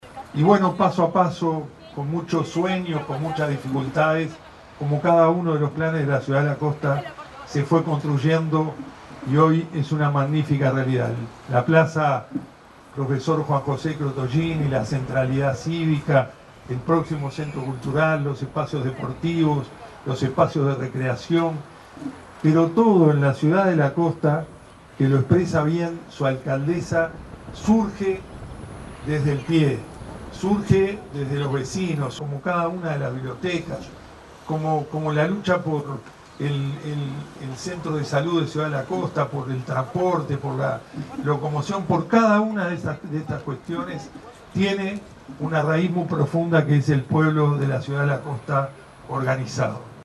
Ciudad de la Costa festejó sus 28 años con un acto en la Plaza Crottogini, ubicada en el km. 20.500 de la Avenida Giannattasio.
dr._marcos_carambula.mp3